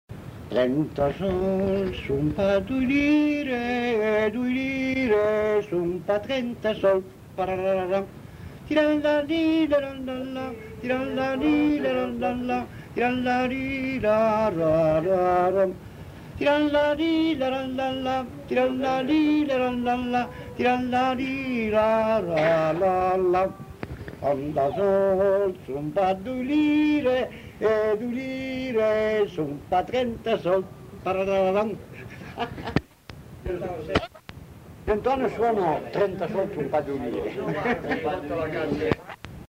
Aire culturelle : Val Varaita
Lieu : Bellino
Genre : chant
Effectif : 1
Type de voix : voix d'homme
Production du son : fredonné ; chanté